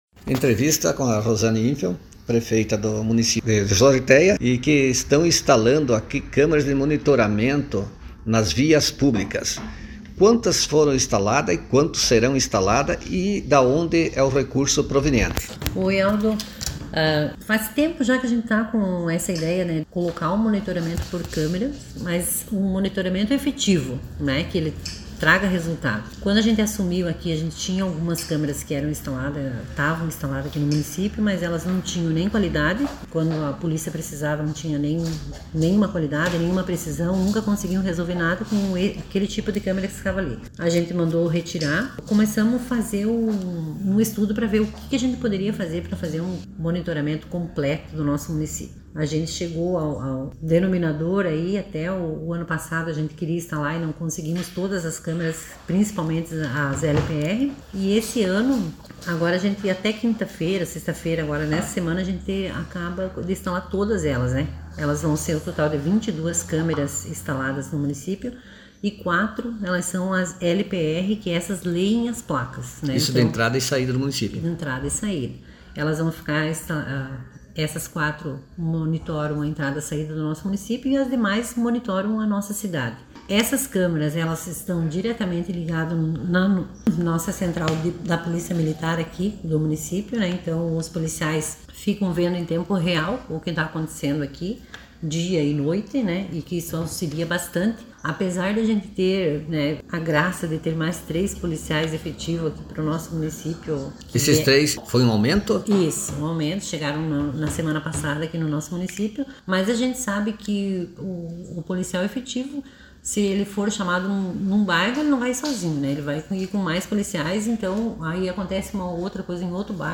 Áudio_com_a_prefeita_de_Zortéa,_Rosane_Infeld..mp3